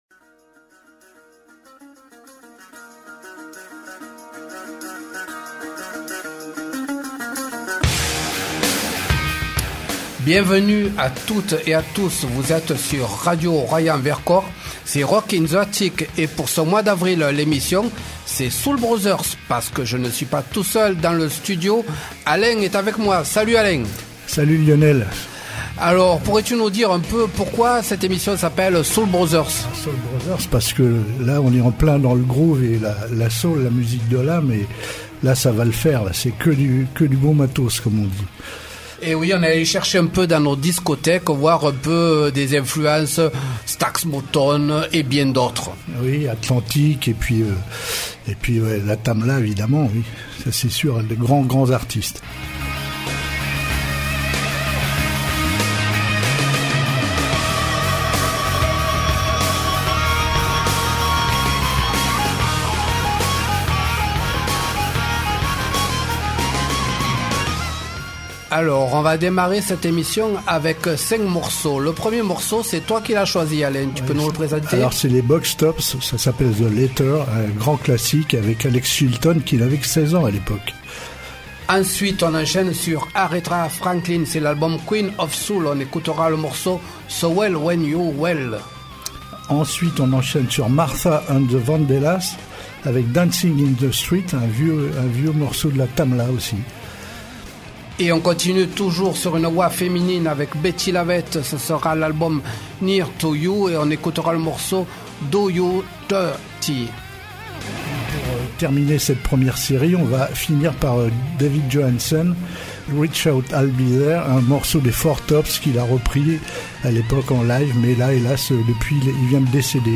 Nous croisons nos discothèques dans le registre musical de la soul music. Nous vous proposons de grands artistes, du groove et des moments cultes.